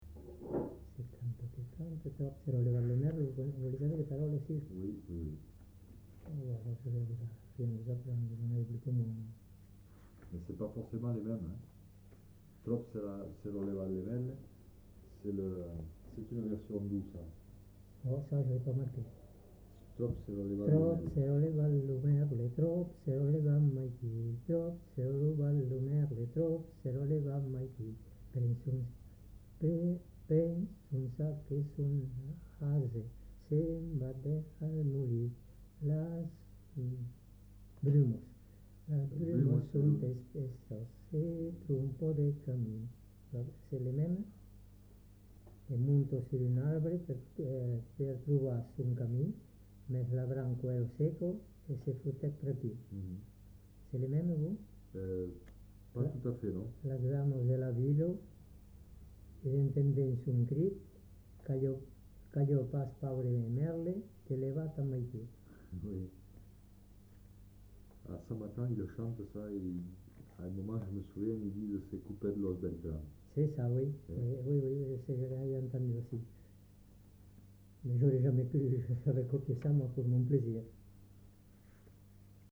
Lieu : Auterive
Genre : chant
Effectif : 1
Type de voix : voix d'homme
Production du son : lu
Description de l'item : fragment ; 3 c. ; refr.